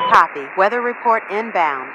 Radio-atcWeatherUpdate2.ogg